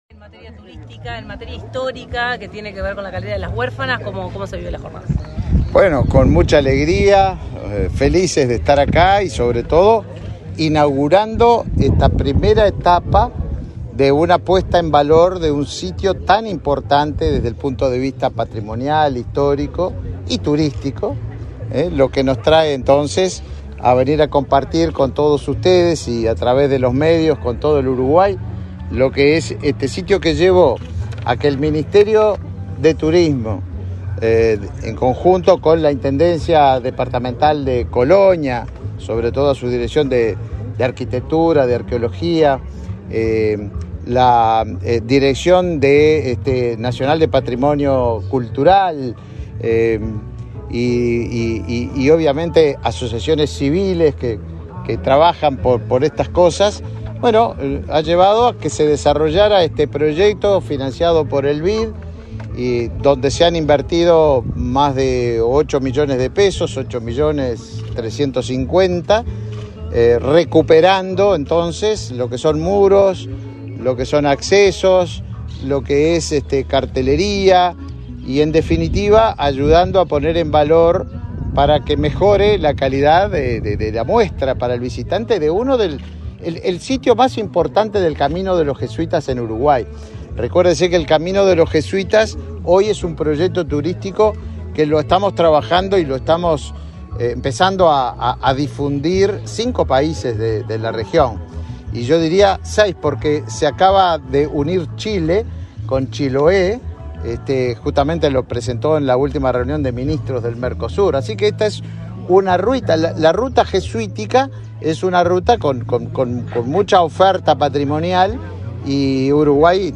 Declaraciones a la prensa del ministro de Turismo, Tabaré Viera
El ministro de Turismo, Tabaré Viera, participó, este 27 de noviembre, en la inauguración de obras en Calera de las Huérfanas, en la ciudad de Carmelo